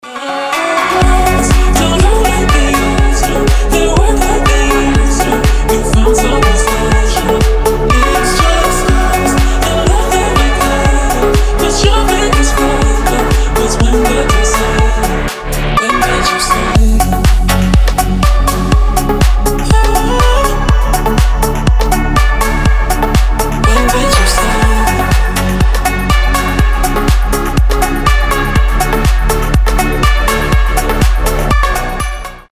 классный дип